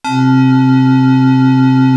BELDANDY C2.wav